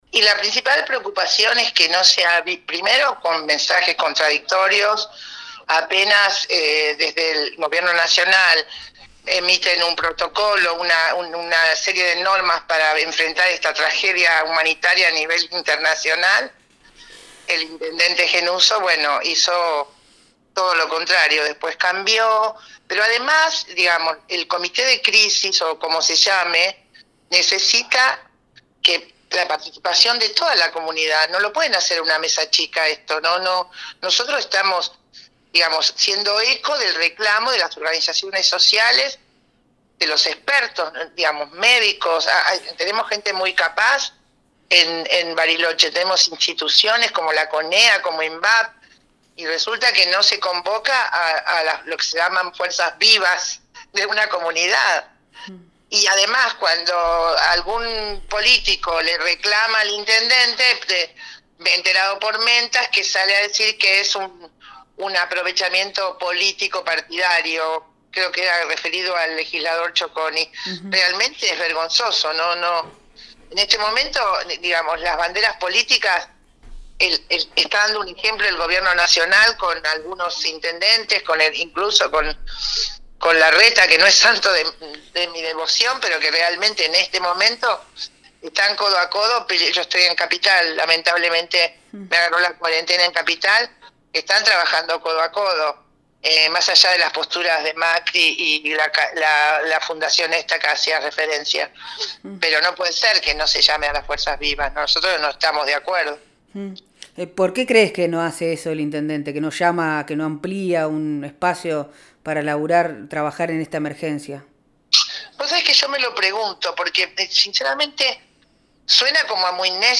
en diálogo con Proyecto Erre habló sobre la Carta Abierta a Gustavo Gennuso. Se manifiesta preocupación por el manejo de la crisis por Coronavirus en la ciudad, y se solicita participación de la Mesa de trabajo colectivo.